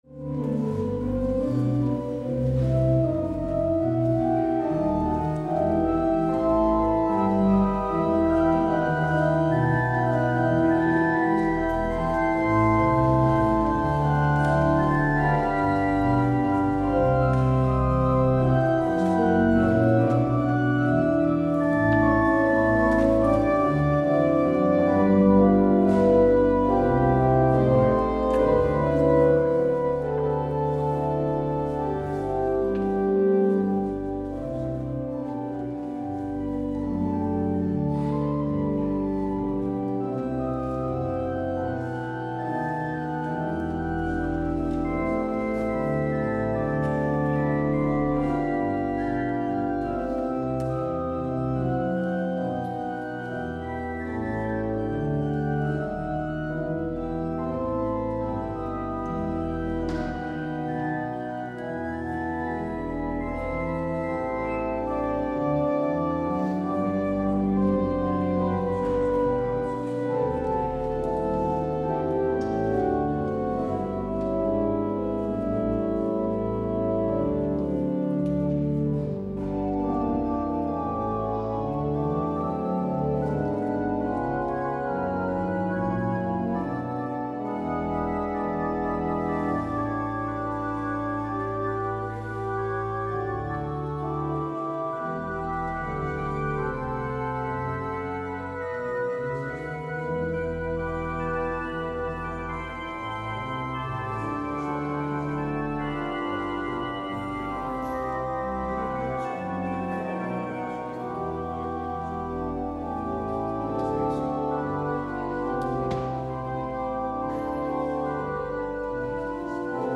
Het openingslied is: Psalm 100 (OB): 1, 3 en 4.
Het slotlied is: NLB 705: 1-4.